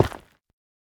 Minecraft Version Minecraft Version snapshot Latest Release | Latest Snapshot snapshot / assets / minecraft / sounds / block / netherrack / break5.ogg Compare With Compare With Latest Release | Latest Snapshot
break5.ogg